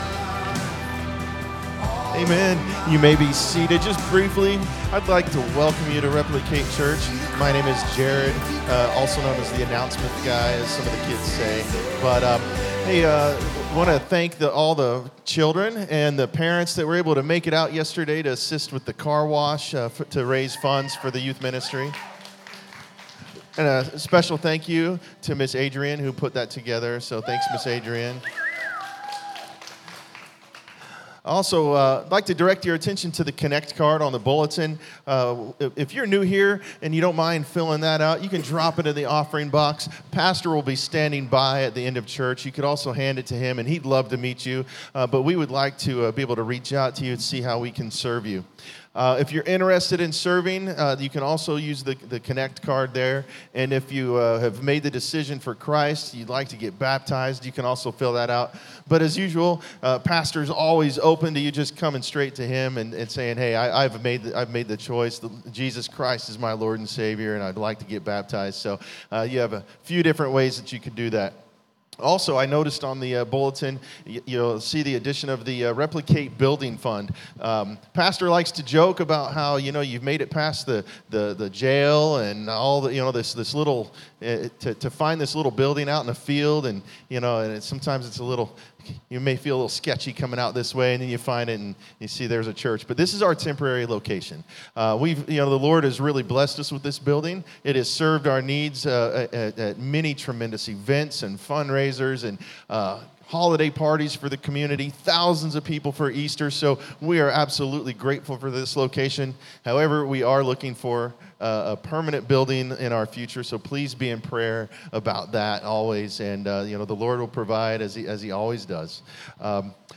Sermons | Replicate Church